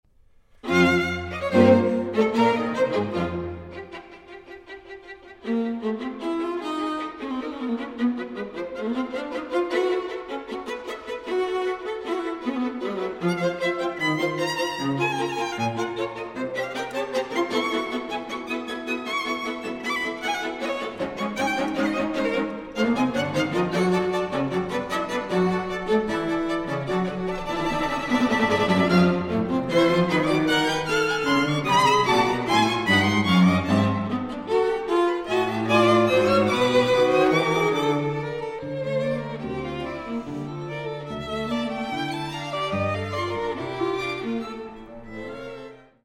inventively contrapuntal, lyrical and energetic in turns
The acoustic is apt and not inflated
with good balance, accurate intonation and rhythms